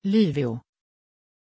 Aussprache von Lyvio
pronunciation-lyvio-sv.mp3